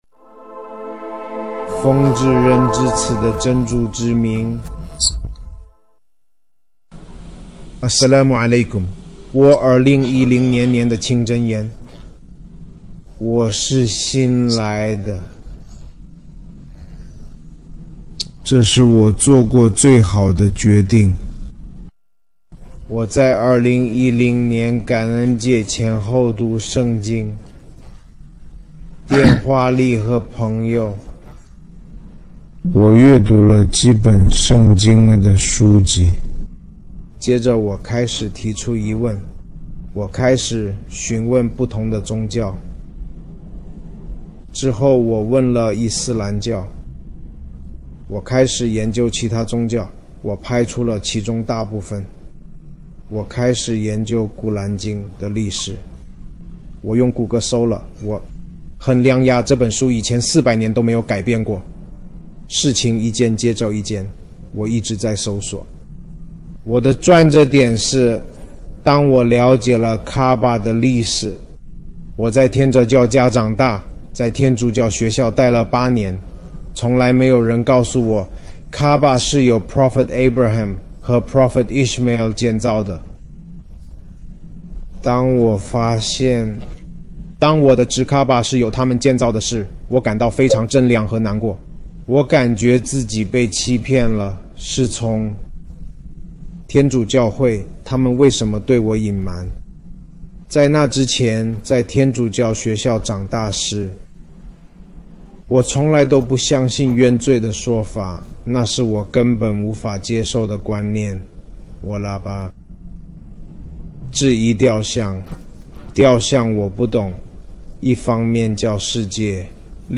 视频 新穆斯林故事 女性